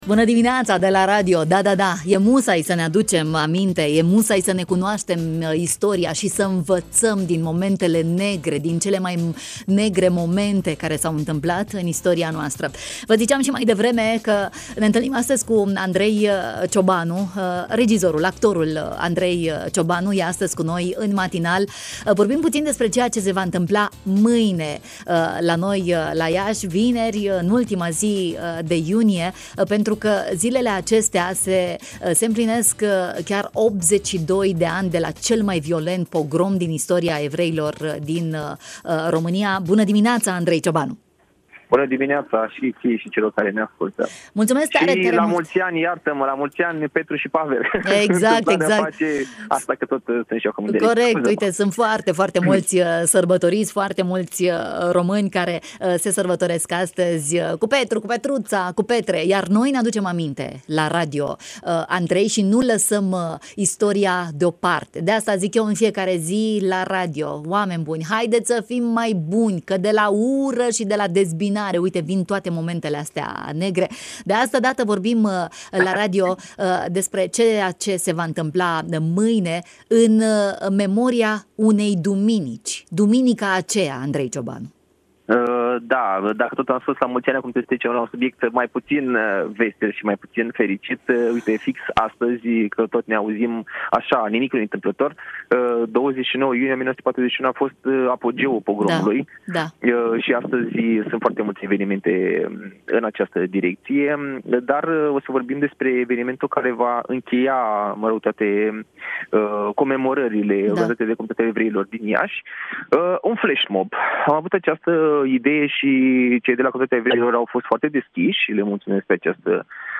în matinalul de la Radio România Iași